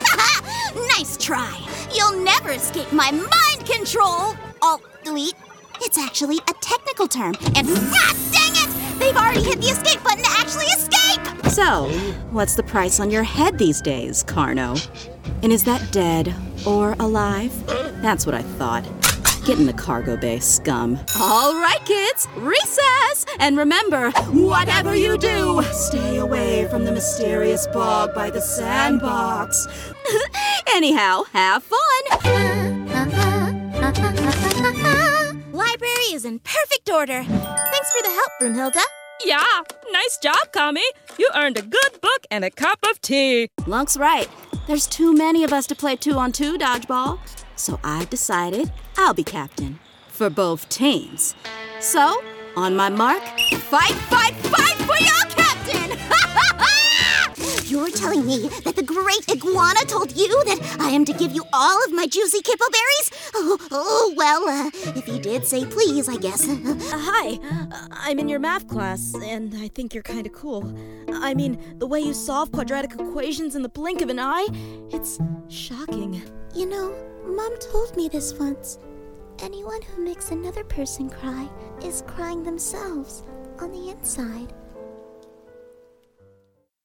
Voiceover
Animation